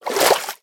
sounds / liquid / swim2.ogg
swim2.ogg